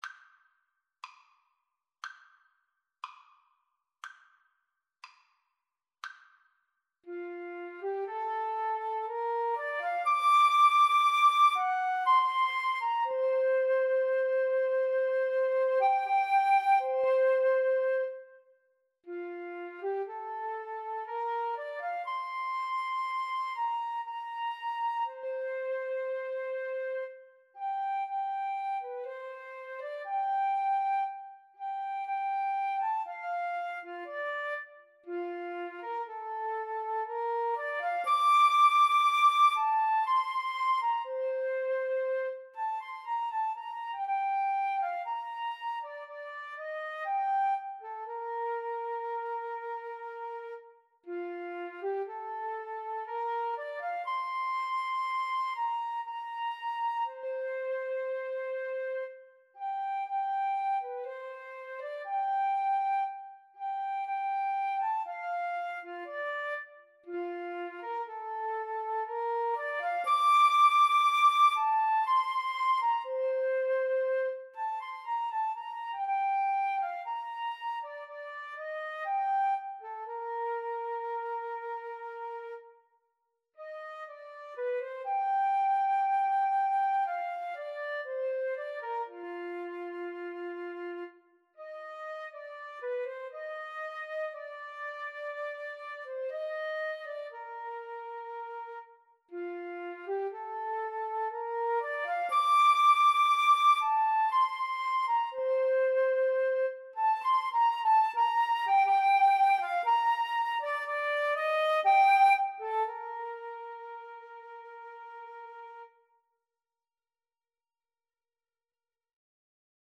2/4 (View more 2/4 Music)
Andante =60
Classical (View more Classical Flute-Cello Duet Music)